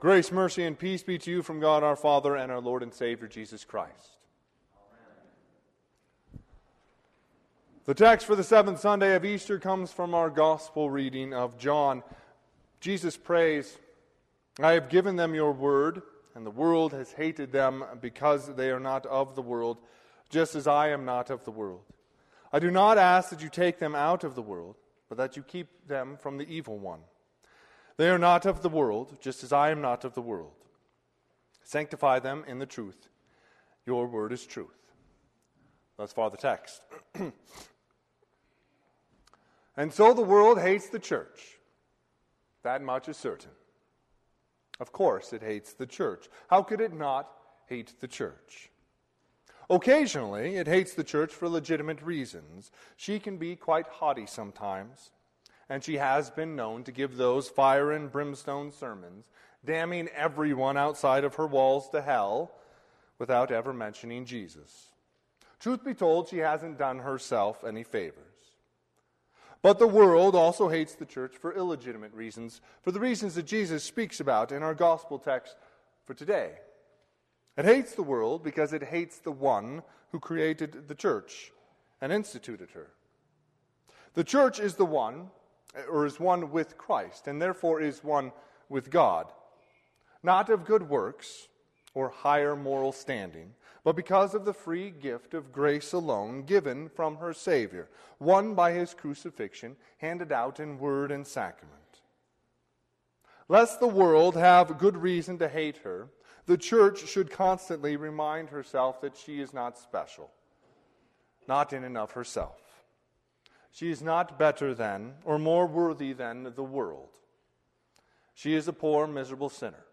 Sermon - 5/16/2021 - Wheat Ridge Lutheran Church, Wheat Ridge, Colorado
Seventh Sunday of Easter